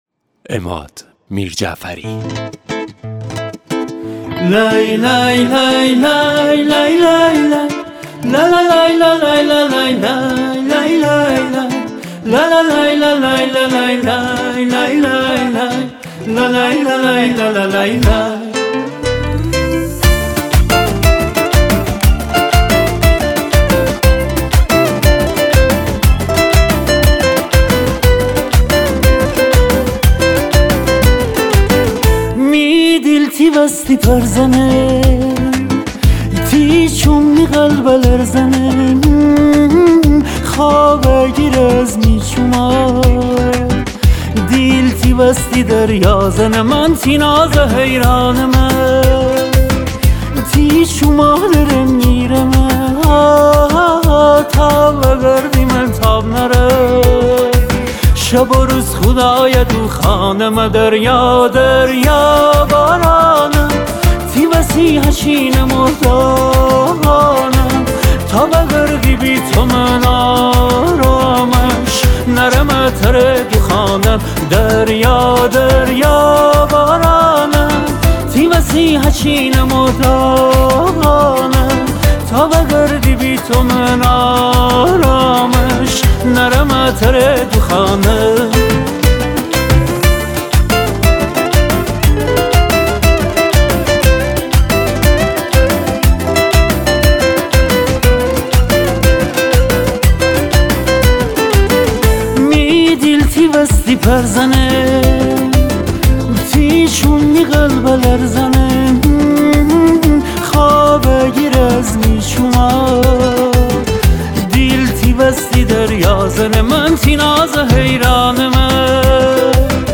ریتمیک ( تکدست )